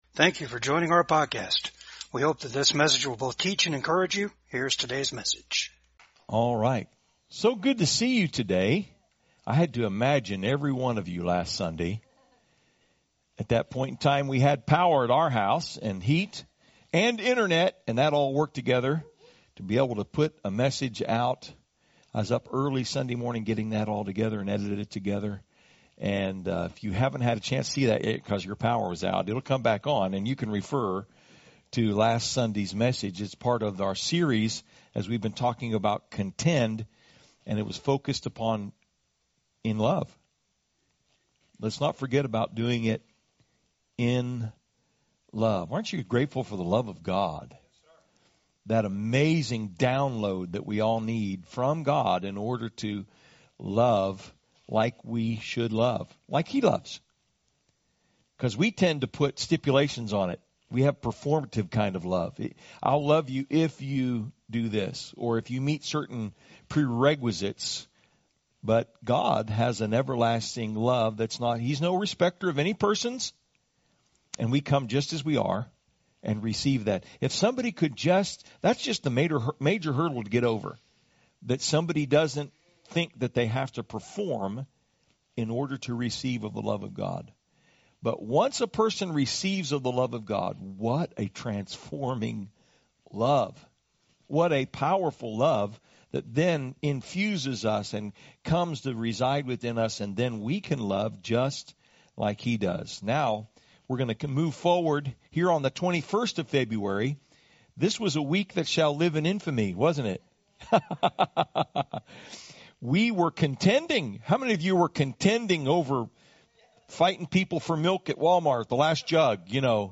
Jude 3 Service Type: VCAG SUNDAY SERVICE WE HAVE MET OUR GREATEST ENEMY AND IT IS US